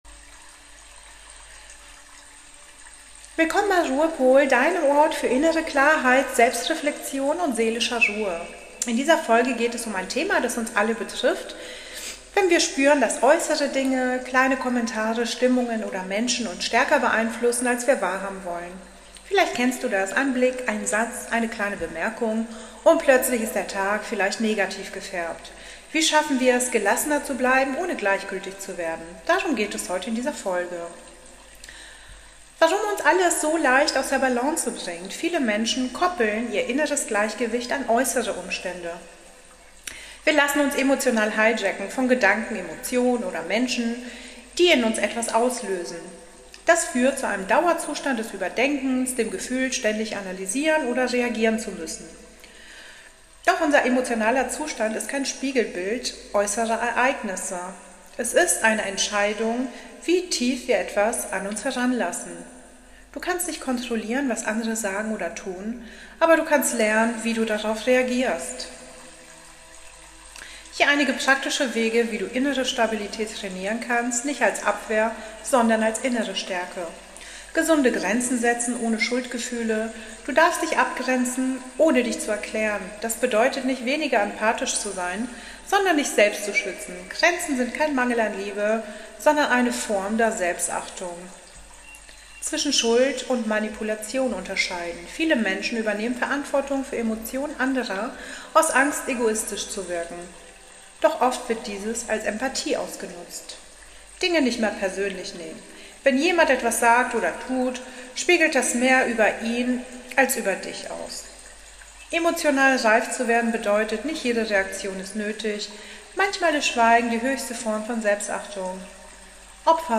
Meditation – Die Stille als innerer Ort der Würde Zum Abschluss führt dich eine sanfte Meditation in die Tiefe: Ein Raum jenseits von Leistung, Vergleich und Urteil – dorthin, wo du einfach bist.